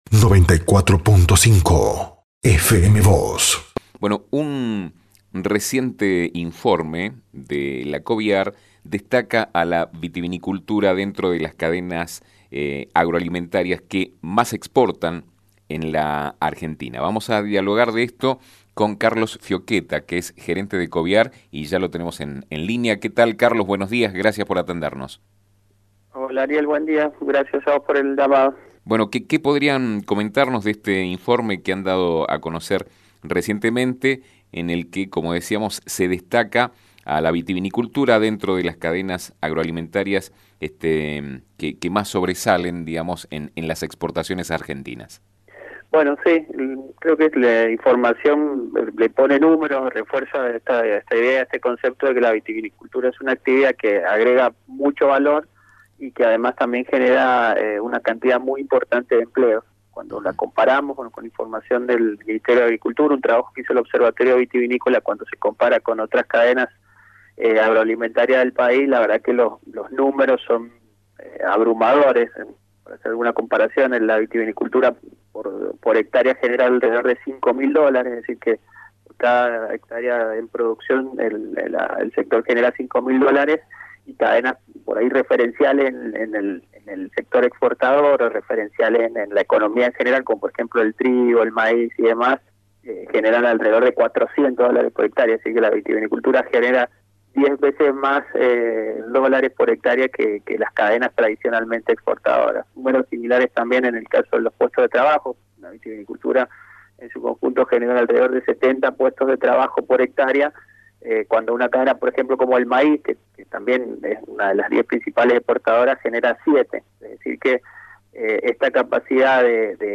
Un reciente informe de la Coviar destaca que la vitivinicultura está dentro de las cadenas agroalimentarias que más exportan en Argentina. Sobre este tema dialogó con FM Vos (94.5) y Diario San Rafael